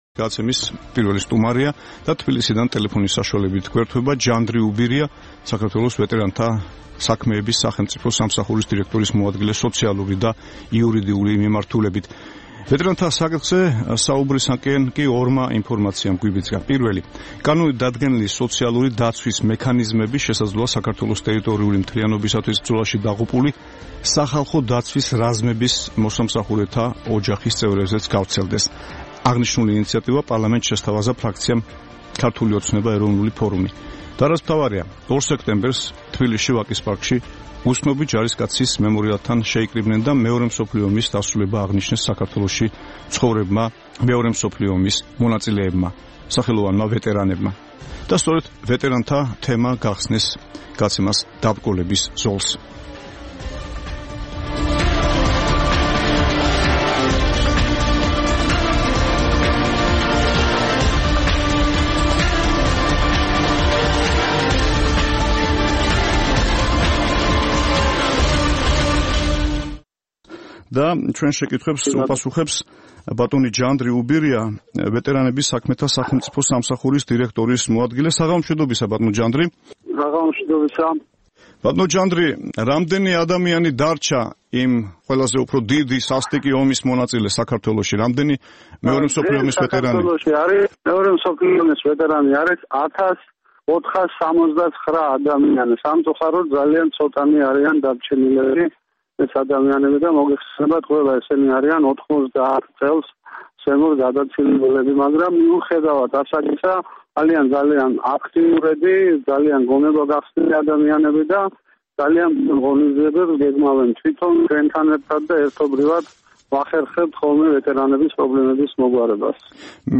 საუბარი ჯანდრი უბირიასთან
საქართველოში მყოფ სამი სხვადასხვა თაობის ომის ვეტერანთა სოციალურ და სამართლებრივ მდგომარეობაზე საუბრობს ჯანდრი უბირია, საქართველოს ვეტერანთა საქმეების სახელმწიფო სამსახურის დირექტორის მოადგილე სოციალური და იურიდიული მიმართულებით.